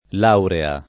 laurea [ l # urea ] s. f.